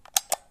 switch33.wav